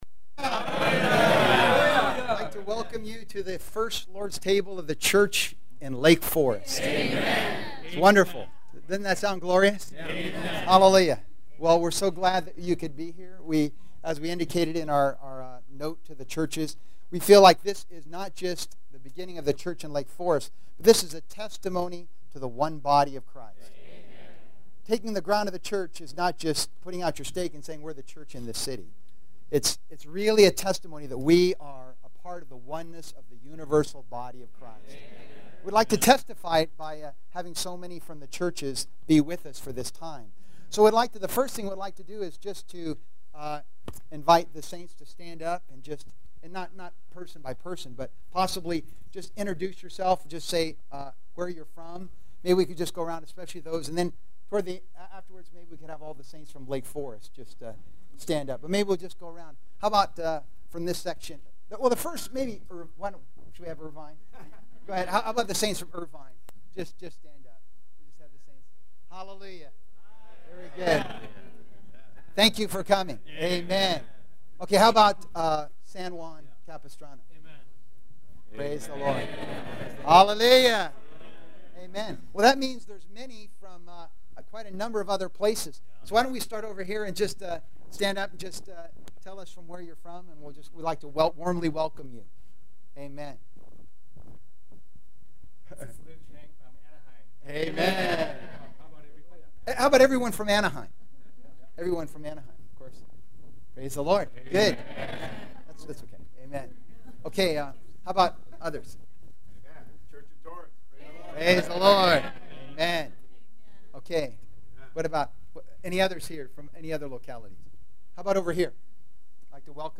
Inaugural Lord’s Table
The first Lord’s Table of the church in Lake Forest was attended by many visiting saints on September 19, 2010. Coworkers from throughout southern California attended and some spoke short or longer words to the church here.